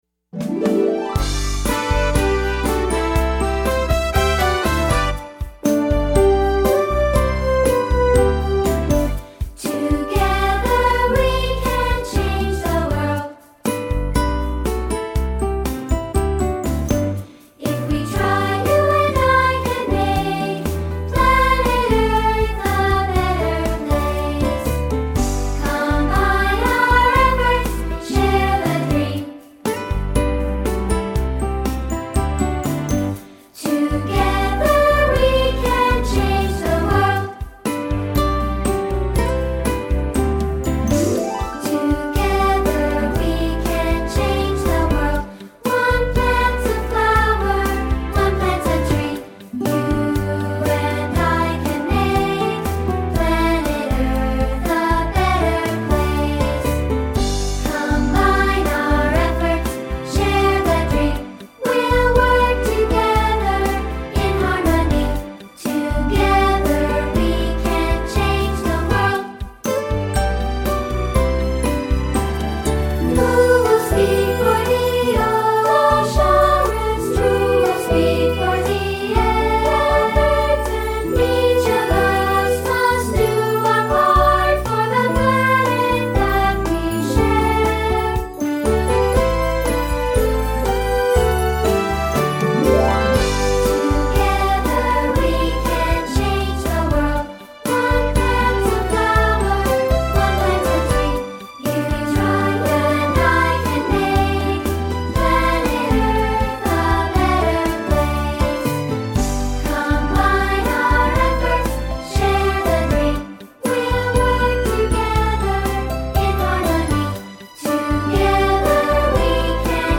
Voicing: Unison|2-Part